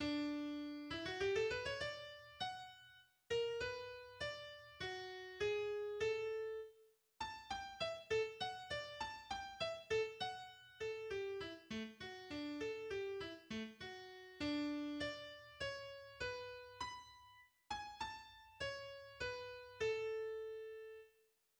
Genre Symphonie
, en ré majeur (trio en sol majeur), 28 + 30 mesures
Première reprise du Menuet :